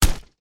ArmorHit01.wav